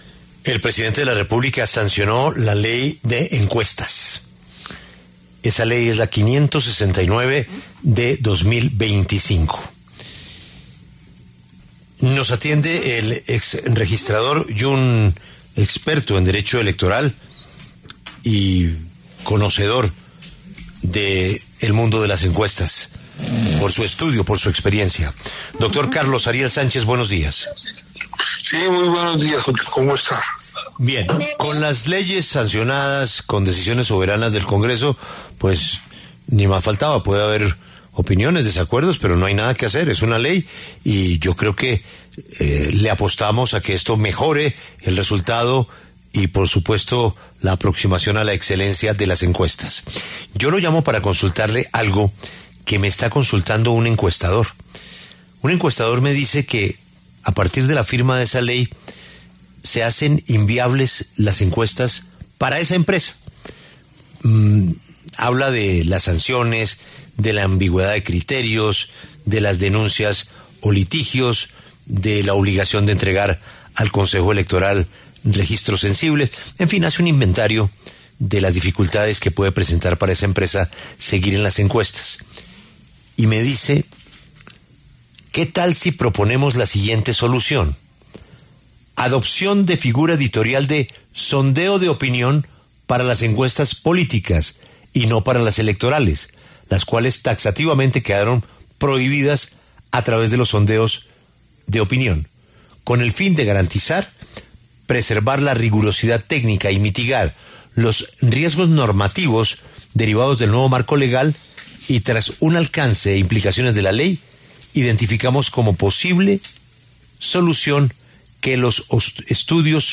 En esa línea, en los micrófonos de La W, con Julio Sánchez Cristo, habló el exregistrador Carlos Ariel Sánchez, quien analizó el tema.